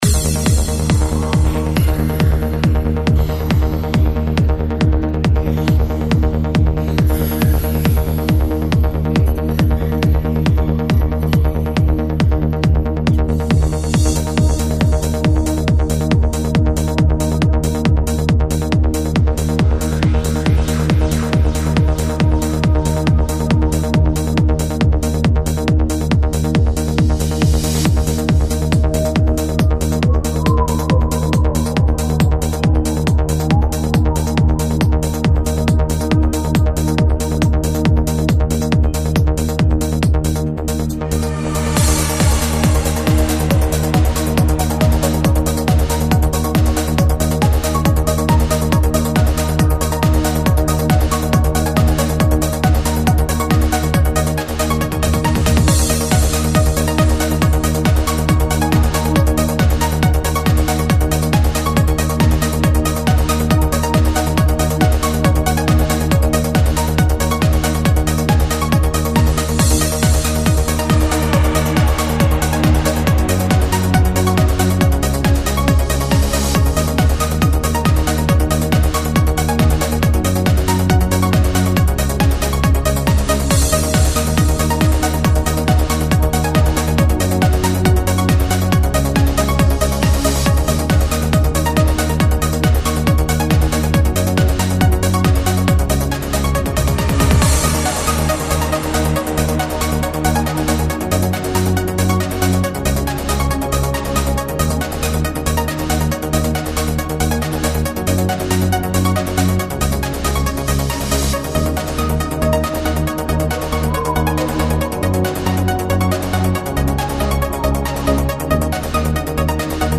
Pure Melodic Trance.